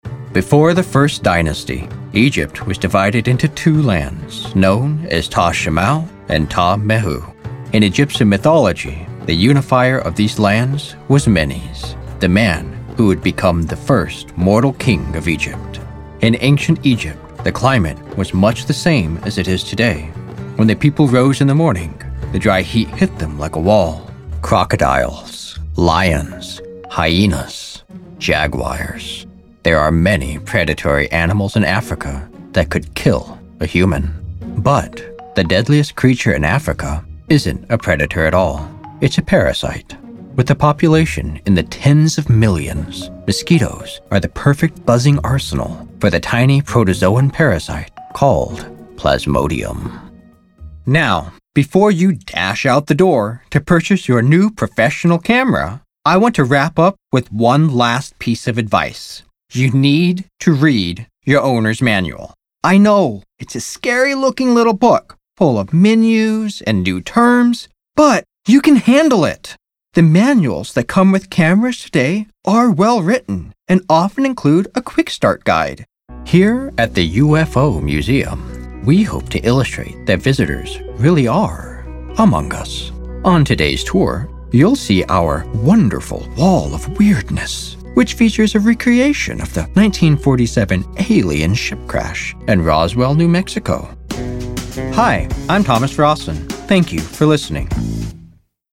Samples that reveal my full range: intimate storytelling, rich narration, and bold character voices.
Narrative Demo
Narrative_Demo.mp3